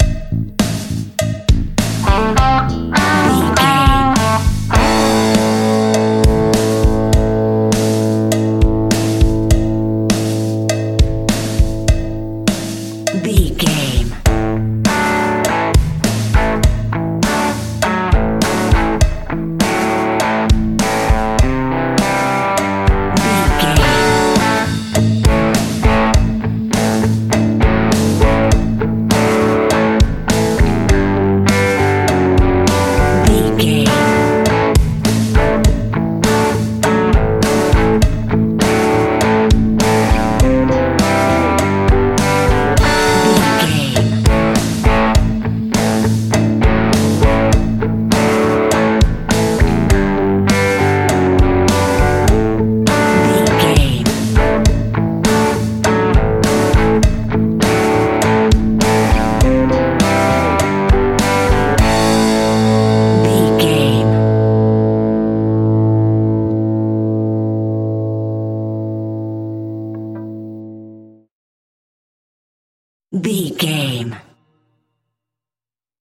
Epic / Action
Fast paced
Mixolydian
hard rock
heavy metal
blues rock
distortion
instrumentals
rock guitars
Rock Bass
Rock Drums
heavy drums
distorted guitars
hammond organ